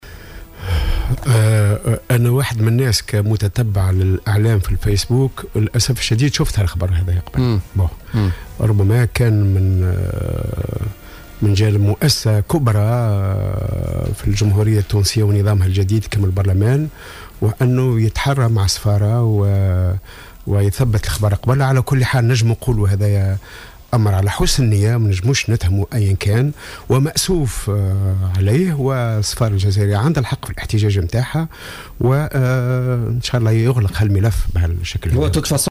وجاءت ملاحظته على هامش لقاء صحفي معه اليوم في "الجوهرة أف أم" ببرنامج "بوليتيكا" في تعليقه على حادثة نعي النواب المناضلة الجزائرية تحت قبة البرلمان وتلاوة الفاتحة على روحها صباح اليوم عند افتتاح جلسة عامة في حين انها لا تزال على قيد الحياة.